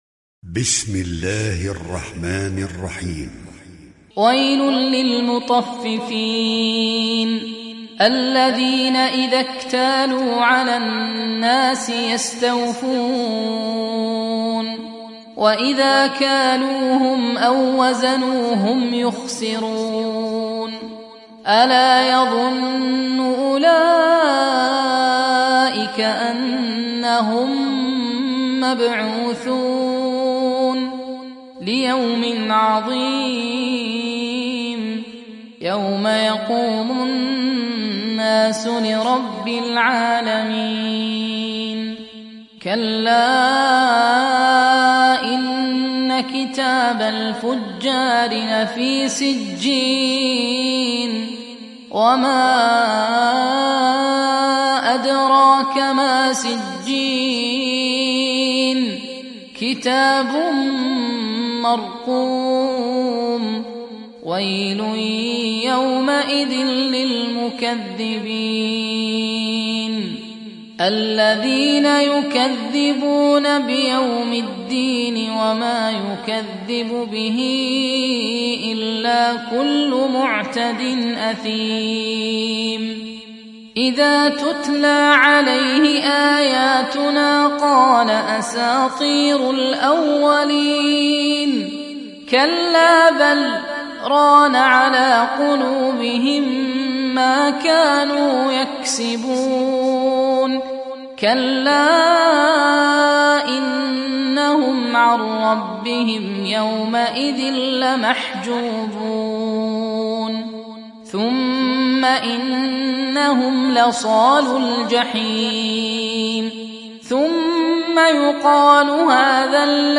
উপন্যাস Hafs থেকে Asim